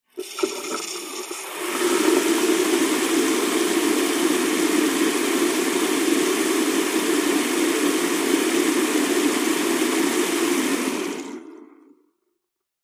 fo_sink_runwater_fast_01hpx
Bathroom sink faucet runs at slow, medium and fast flows.